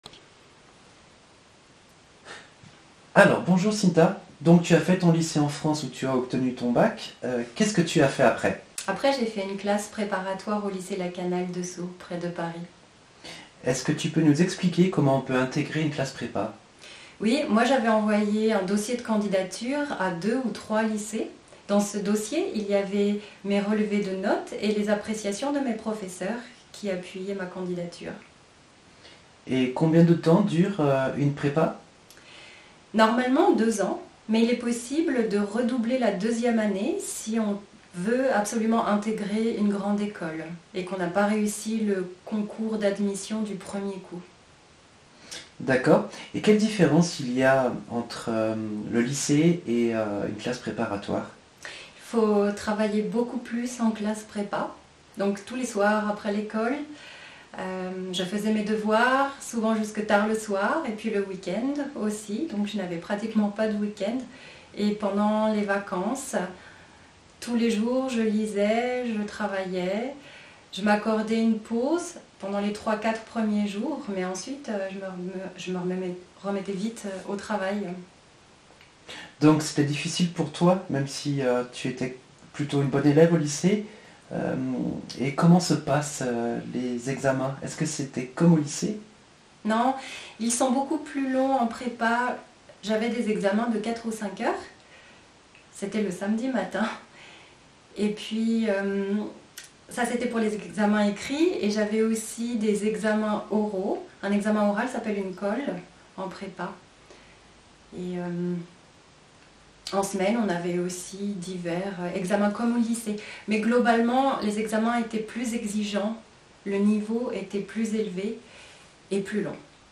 Interview ancienne eleve